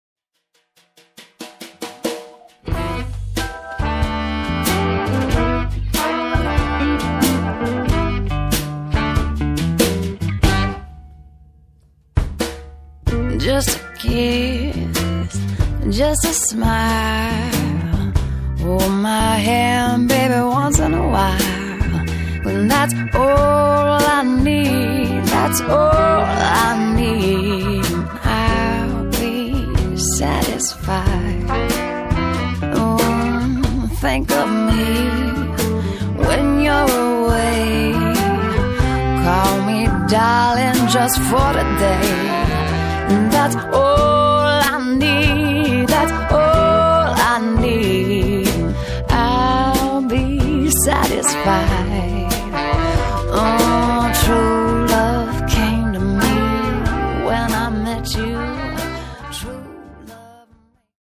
Jazz meets acoustic pop